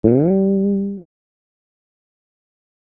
G SLIDE UP.wav